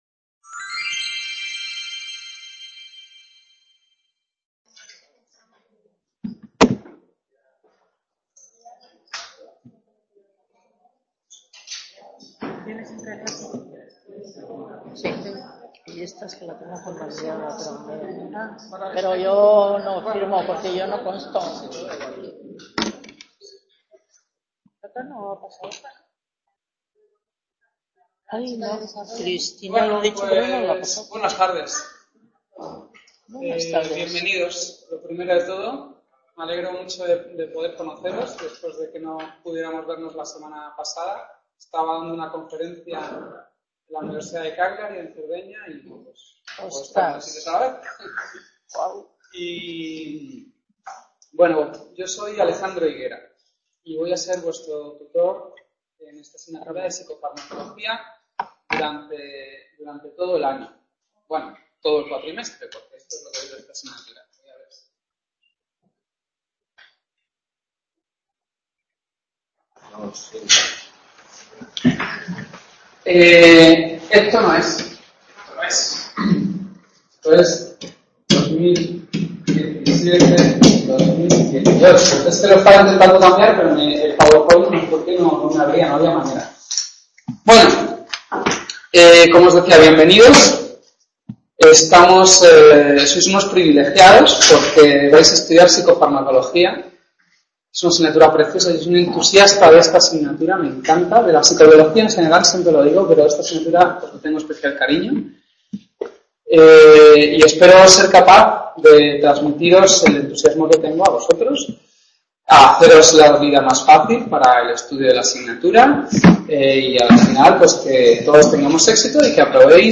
Presentación y Primera Clase Tema 1 | Repositorio Digital